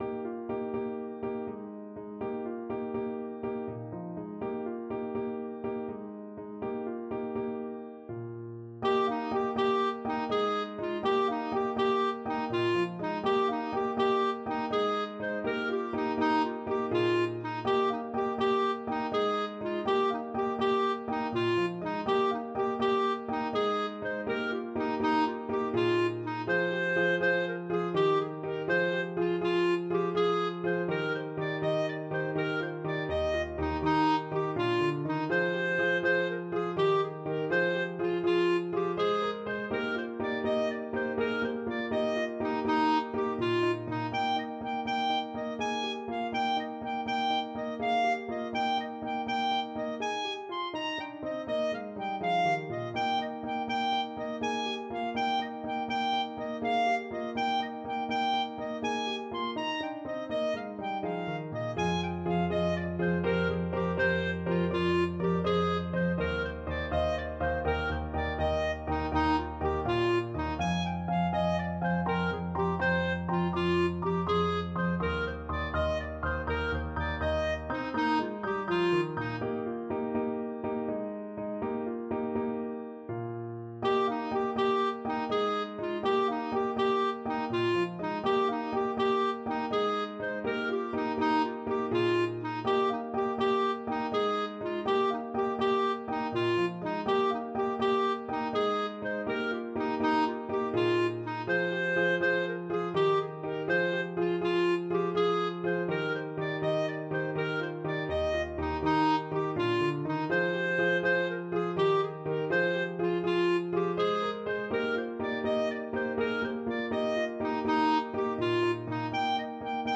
Clarinet
Eb major (Sounding Pitch) F major (Clarinet in Bb) (View more Eb major Music for Clarinet )
9/8 (View more 9/8 Music)
Traditional (View more Traditional Clarinet Music)